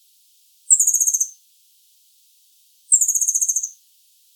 La presencia de estos sonidos puede indicar en algunas especies animales dominancia, apareamiento o cortejo; llamados de alerta al peligro; reconocimiento entre madres y crías etc. Este repositorio digital contiene grabaciones de fauna silvestre residente en la península de Baja California, resultado del proyecto de investigación en el área natural protegida Sierra de la Laguna.
Basilinna_xantussi.mp3